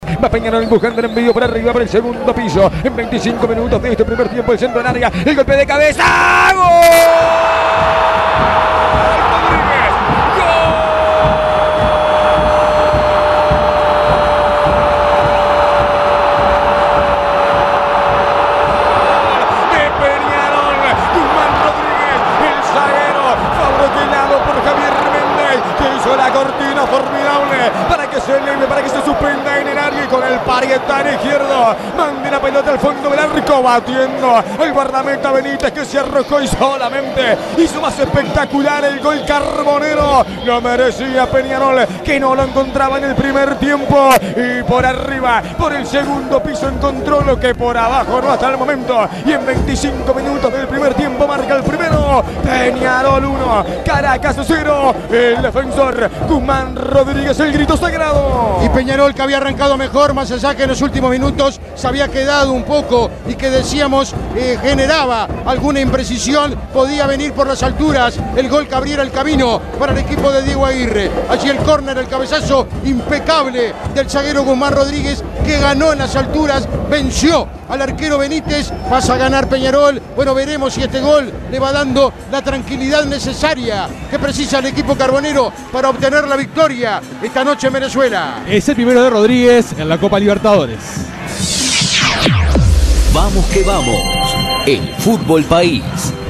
La victoria aurinegra en la voz del equipo de Vamos que Vamos